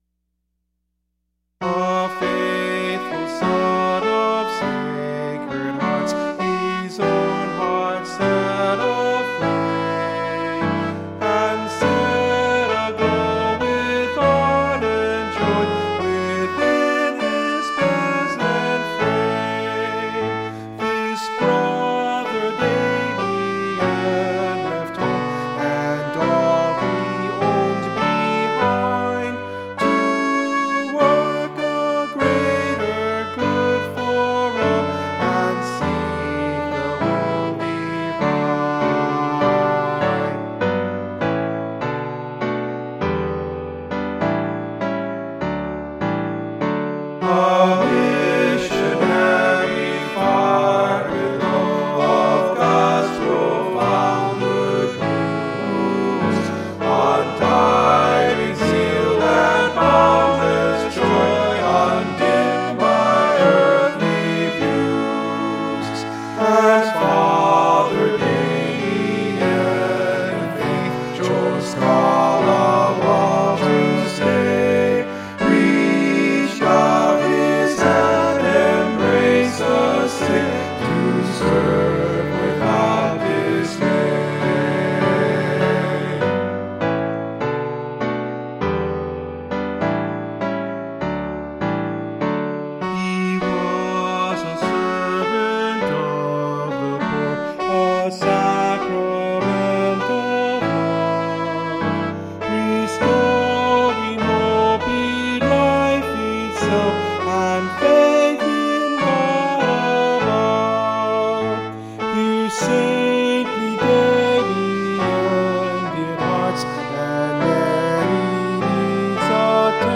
choral arr.